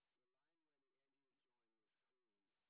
sp22_white_snr30.wav